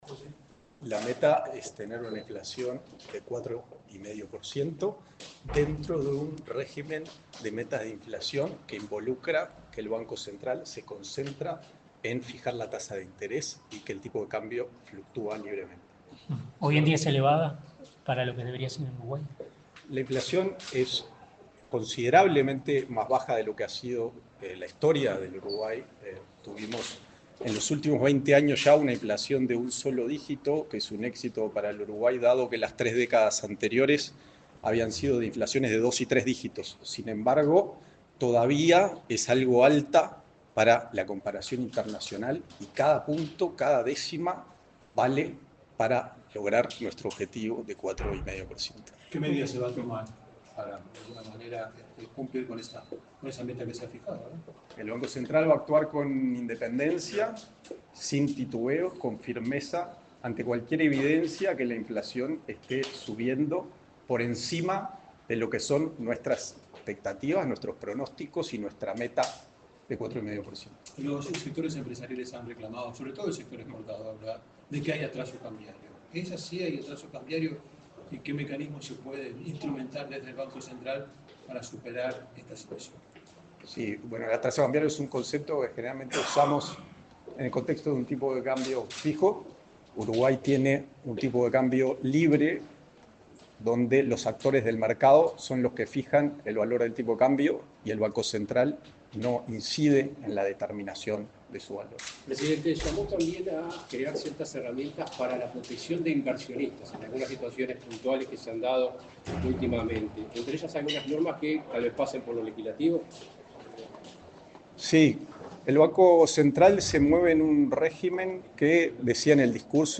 El nuevo presidente del Banco Central del Uruguay (BCU), Guillermo Tolosa, dialogó con la prensa, luego de asumir el cargo en la institución.